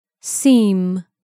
/sim/